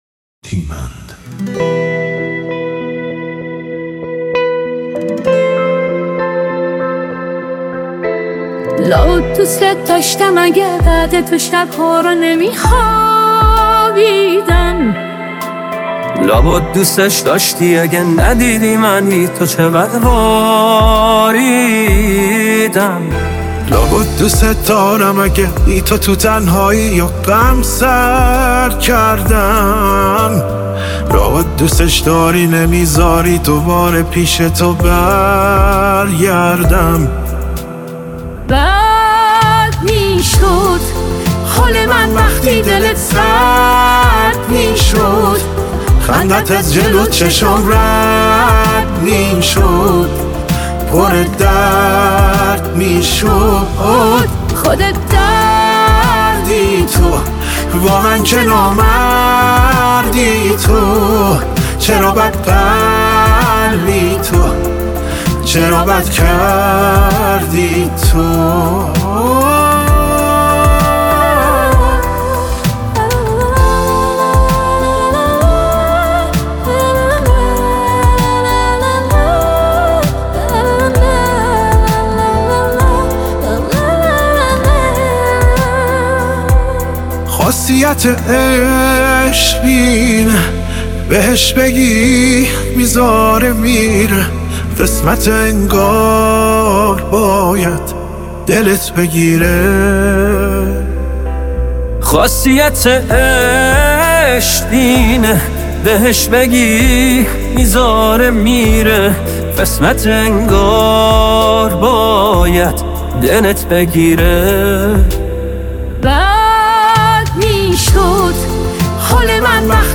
این آهنگ با هوش مصنوعی ساخته شده است